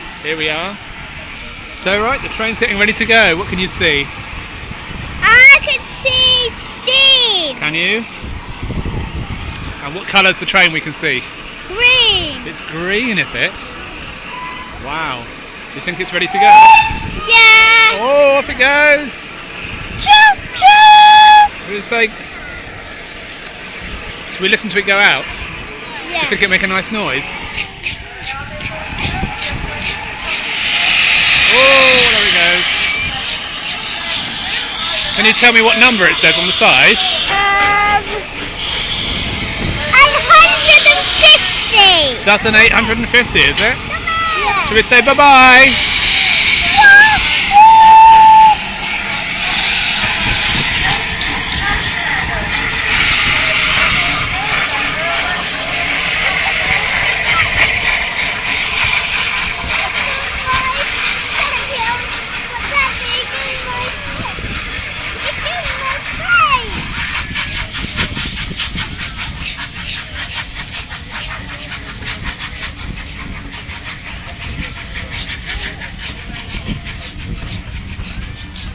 Steam engine leaving Ropley station on the Watercress Line